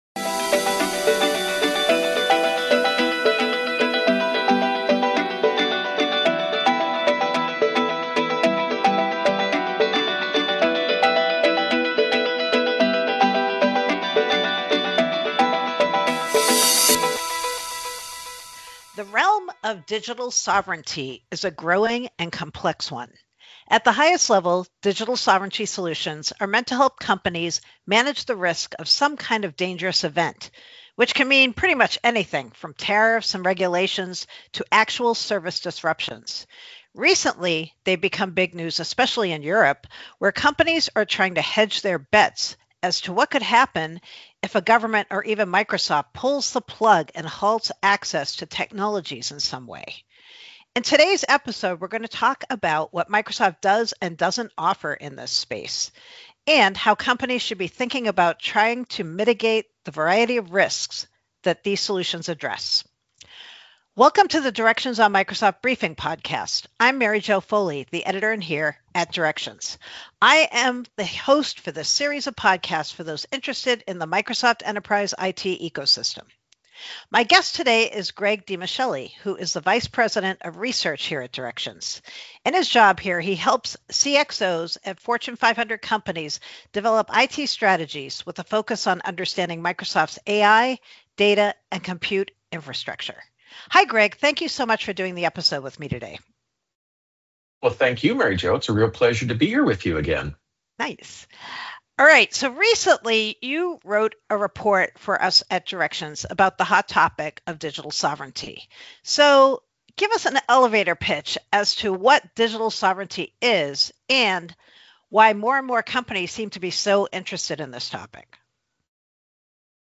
This week on the GeekWire Podcast: We hit the road for a driving tour of the week’s news, making stops at Starbucks, Microsoft, and an Amazon Fresh store in its final days.